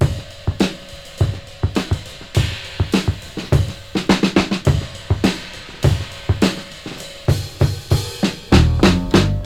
• 103 Bpm Drum Beat F# Key.wav
Free drum loop sample - kick tuned to the F# note. Loudest frequency: 959Hz
103-bpm-drum-beat-f-sharp-key-2wL.wav